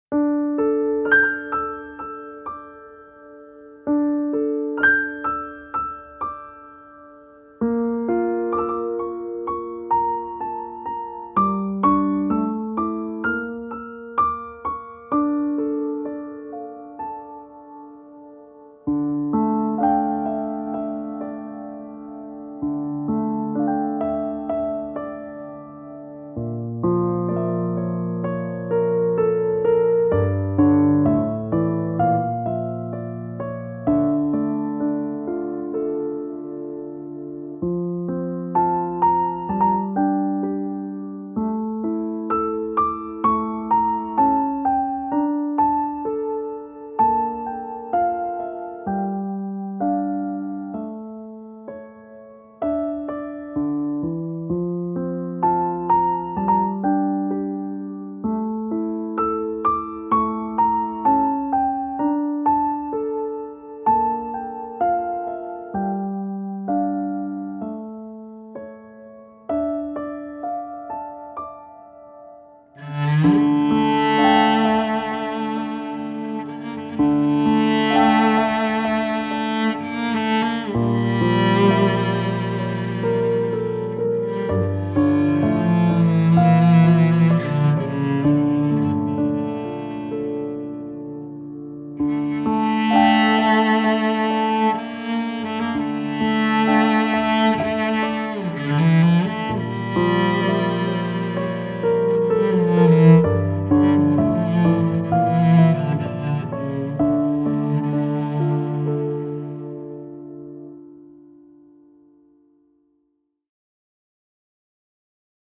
موسیقی بی کلام